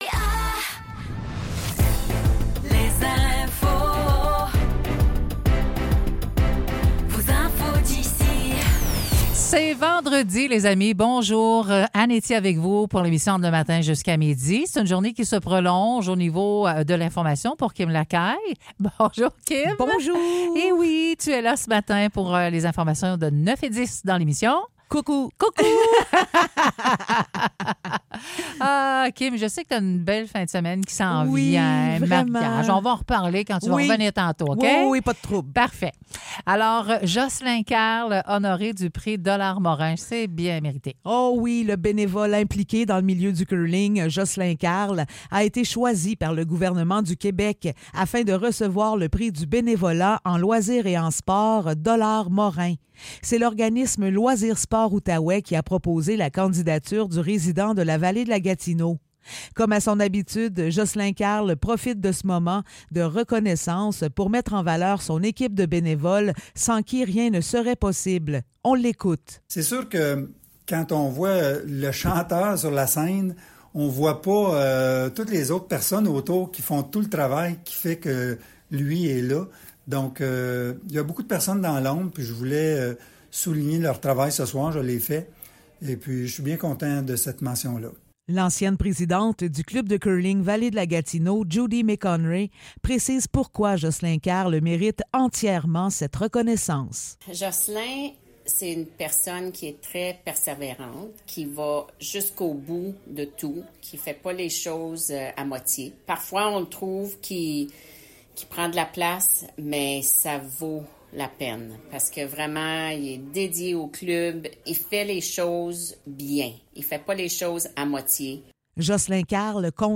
Nouvelles locales - 20 septembre 2024 - 9 h